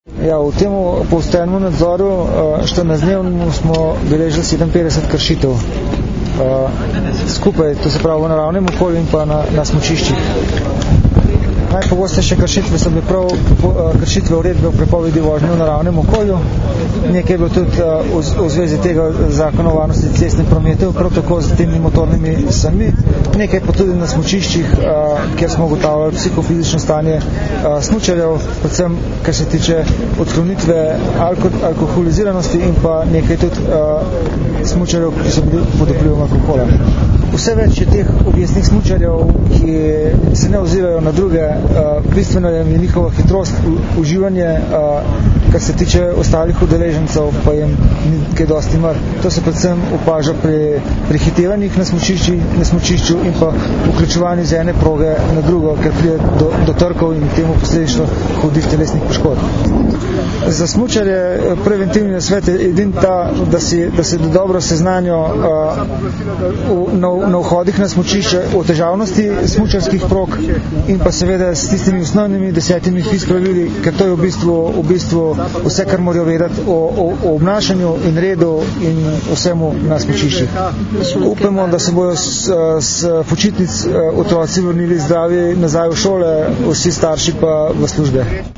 Policija - Kršitve na smučiščih, prepoved vožnje v naravnem okolju - informacija z novinarske konference